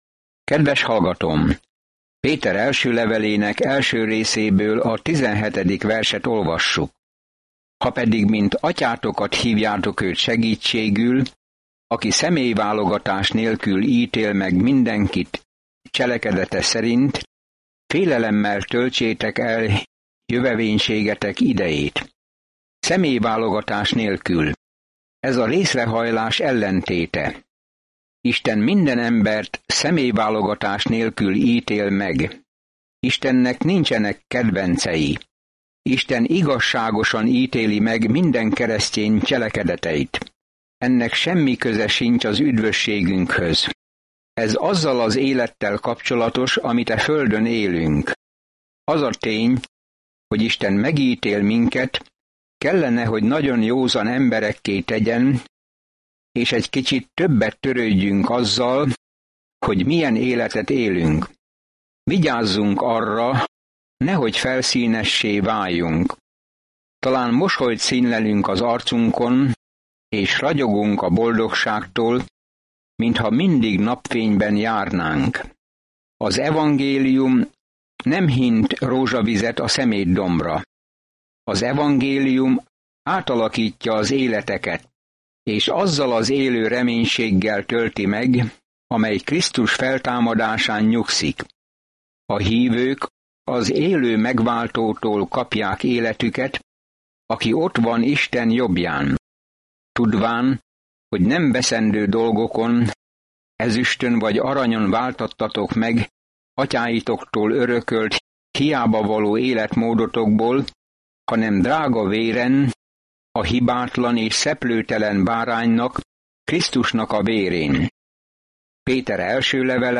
Napi utazás 1 Péteren keresztül, miközben hallgatod a hangos tanulmányt, és olvasol válogatott verseket Isten szavából.